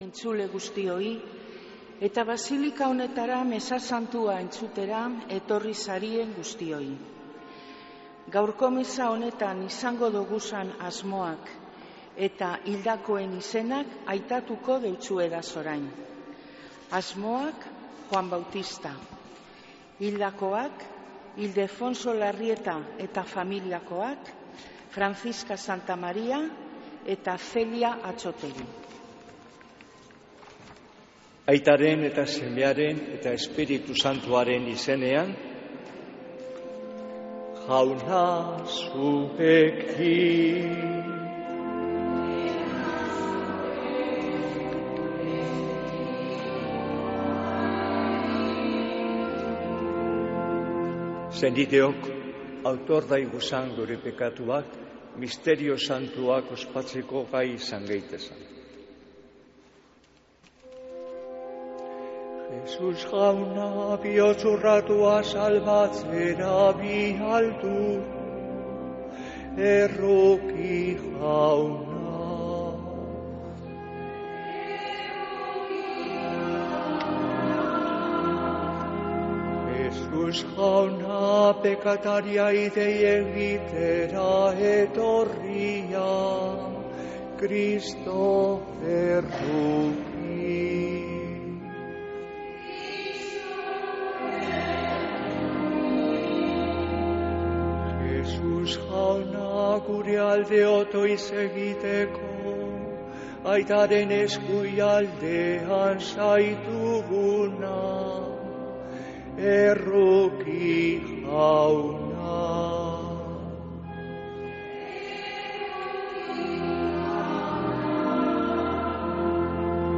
Mezea (24-12-06) | Bizkaia Irratia